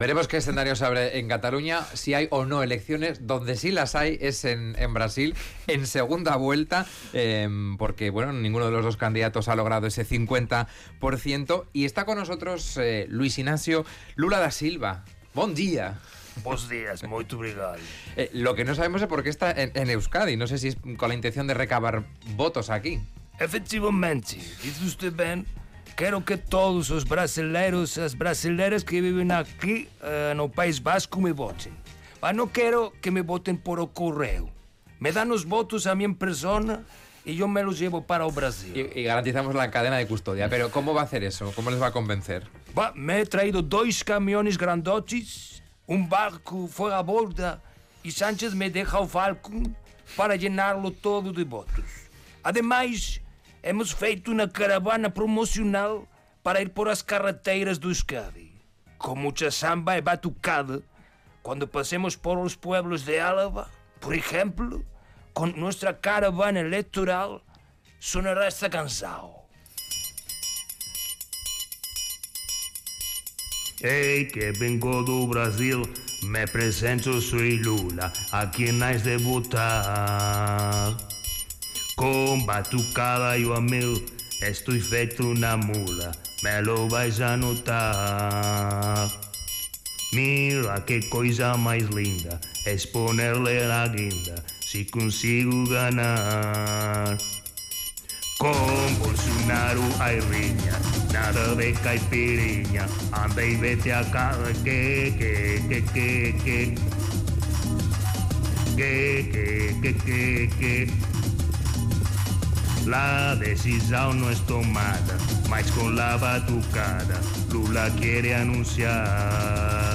Audio: Con el fin de asegurarse la victoria en la segunda vuelta de las elecciones presidenciales brasileñas, el candidato Lula visita Radio Vitoria Gaur en busca del voto de sus compatriotas residentes en Euskadi.